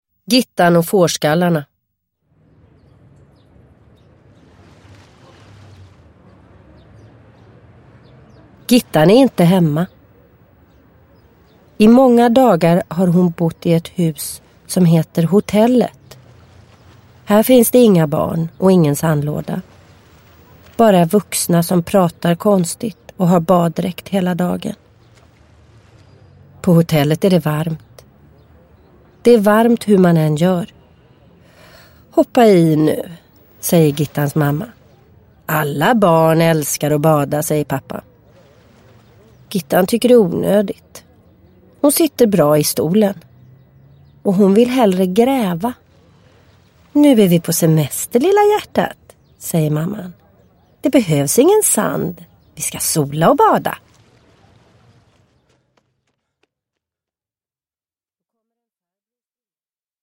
Gittan och fårskallarna – Ljudbok – Laddas ner